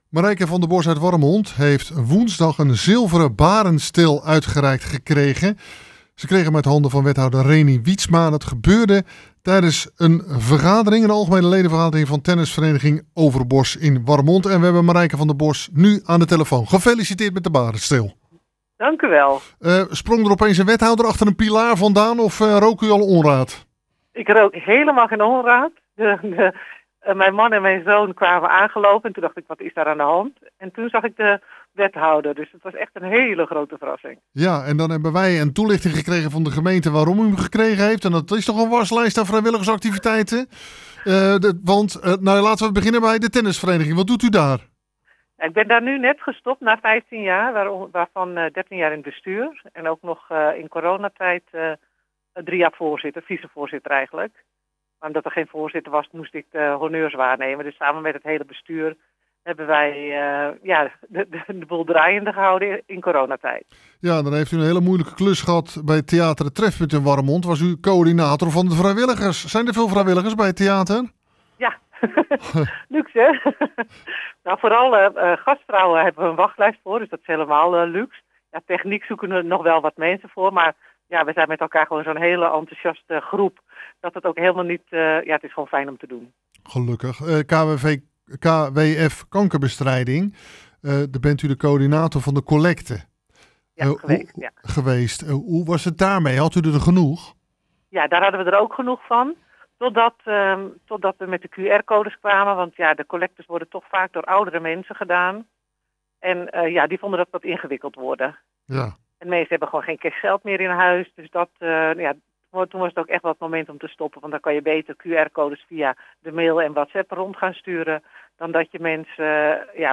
Hieronder het radio-interview: